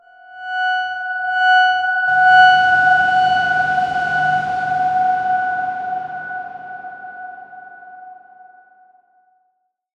X_Darkswarm-F#5-pp.wav